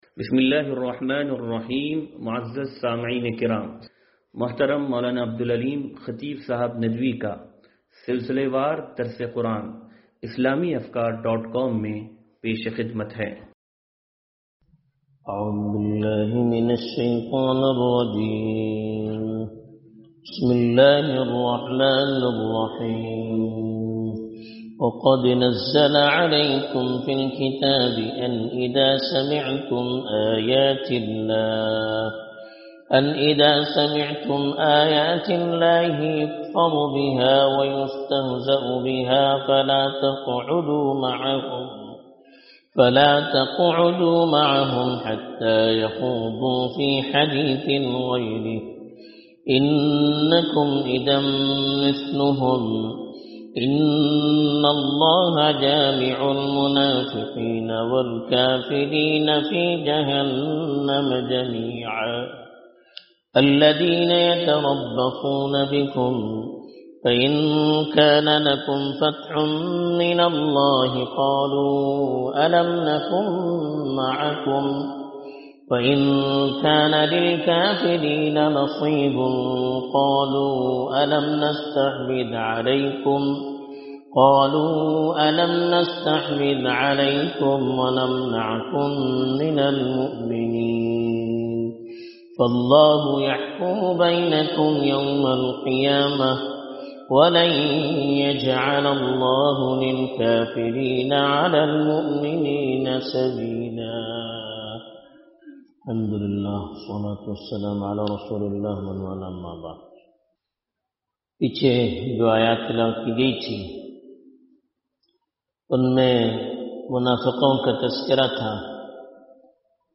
درس-قرآن-نمبر-0406-2.mp3